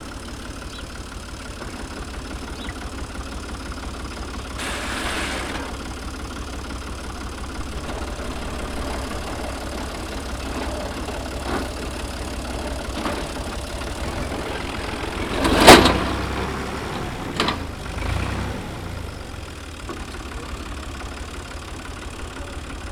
• tractor plowing and swamped.wav
tractor_plowing_and_swamped_Rr4.wav